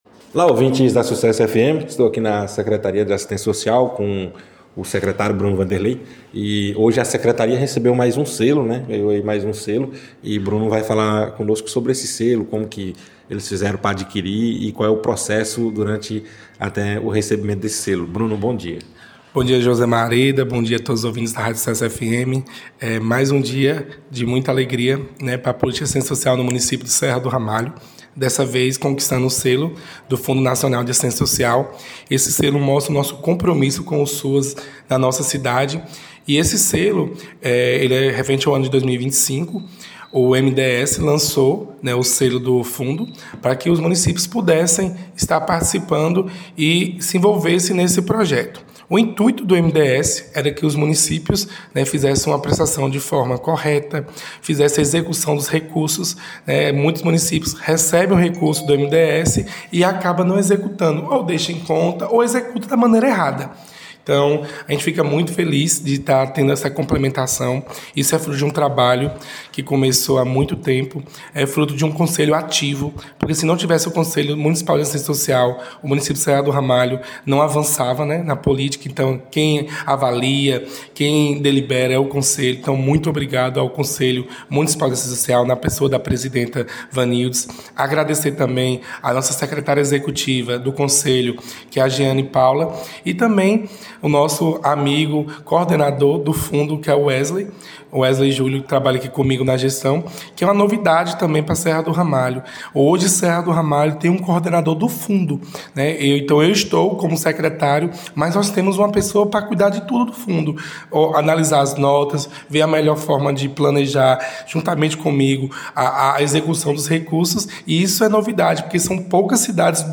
ENTREVISTA-SUCESSO-FM-95.5-BRUNO-VANDERLEI-SECRETARIO-DE-ASSISTENCIA-SOCIAL.mp3